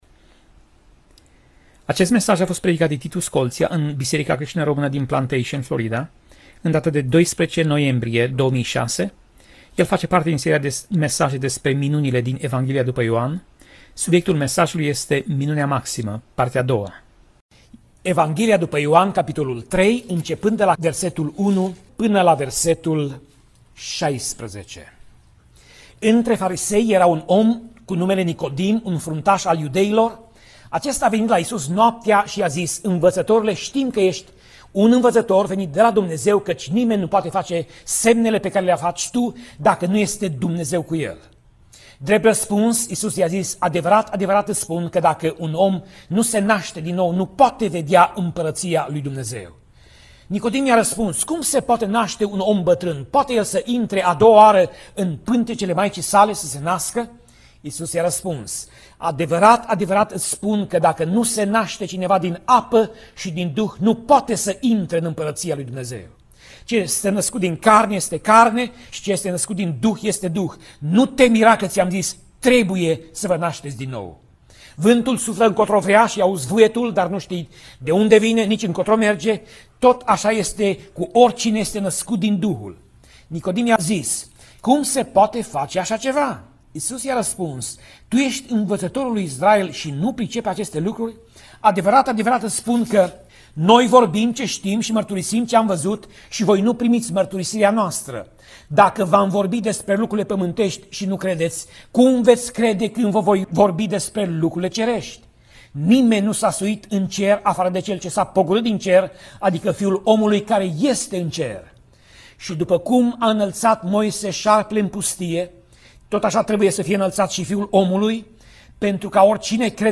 Pasaj Biblie: Ioan 3:1 - Ioan 3:16 Tip Mesaj: Predica